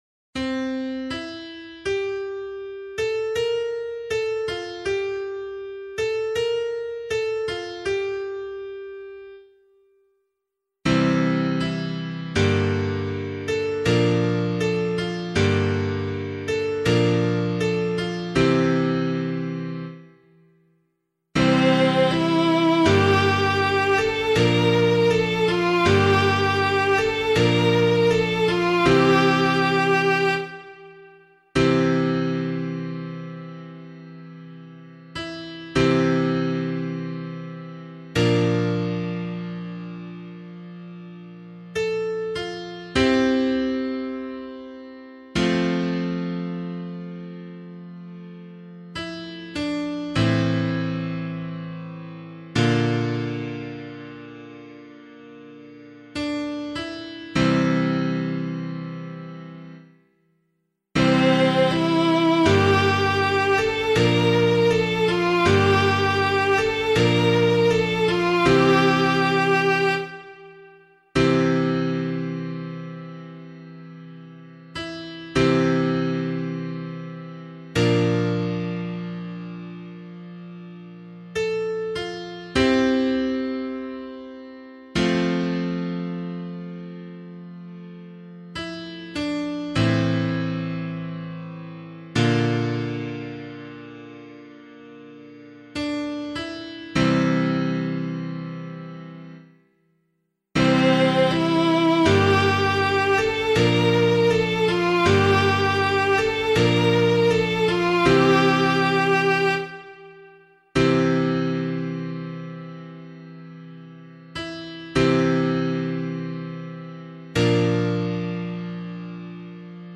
002 Advent 2 Psalm C [APC - LiturgyShare + Meinrad 7] - piano.mp3